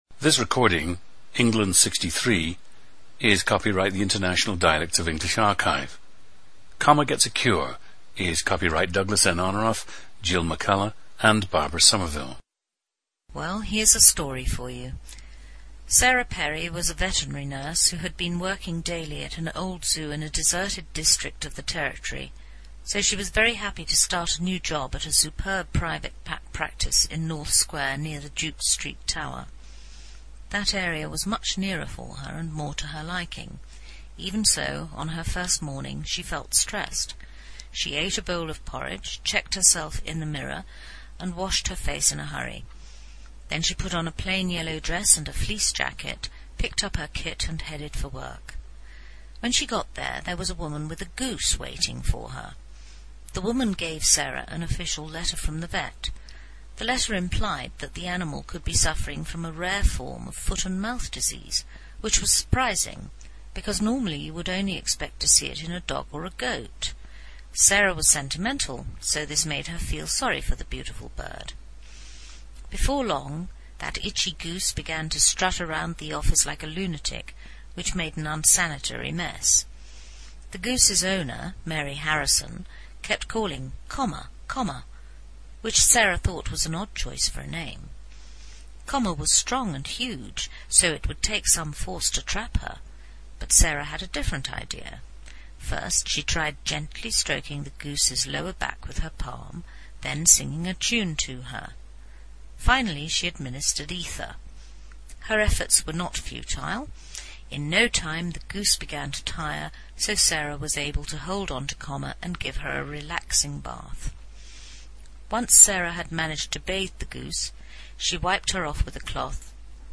Listen to England 63, a woman raised mostly in Surrey, England, but with international influences, who speaks English with a Received Pronunciation dialect.
GENDER: female
The recordings average four minutes in length and feature both the reading of one of two standard passages, and some unscripted speech.